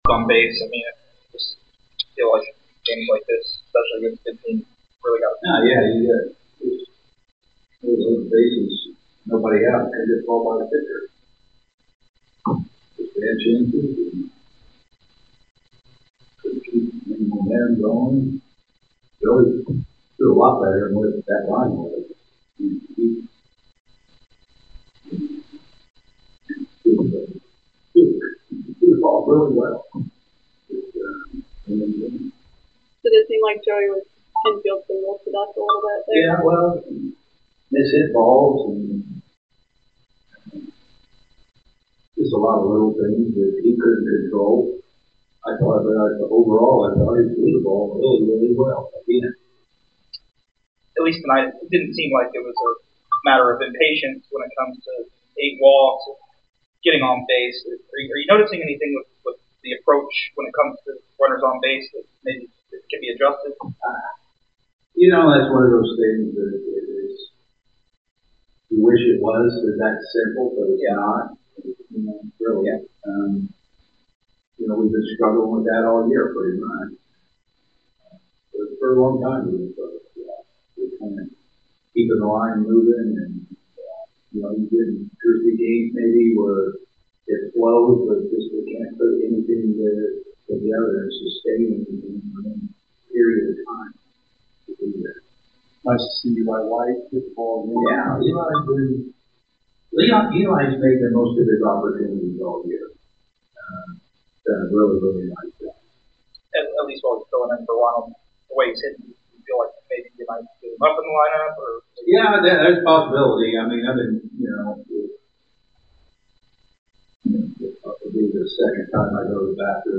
Atlanta Braves Manager Brian Snitker Postgame Interview after losing to the Milwaukee Brewers at Truist Park.